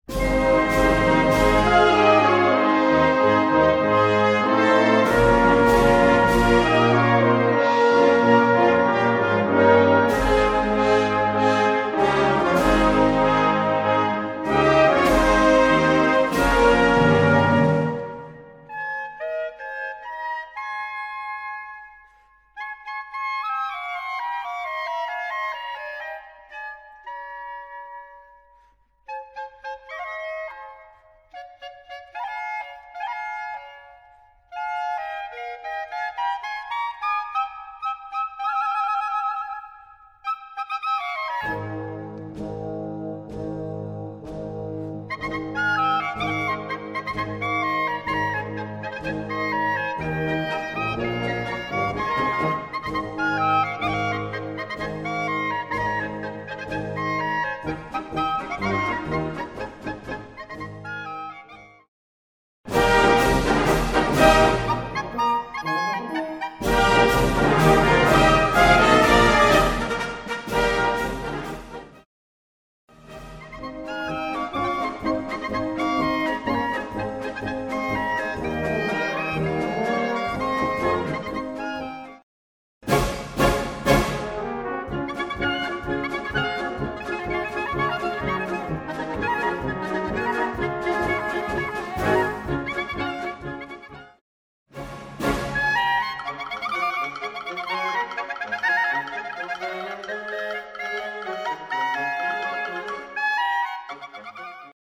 Gattung: Solostück für Es- und B-Klarinette
Besetzung: Blasorchester
und Blasorchester.